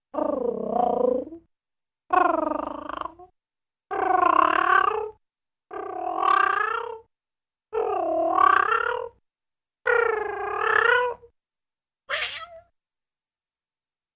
Cat Purring with Vocal Meows.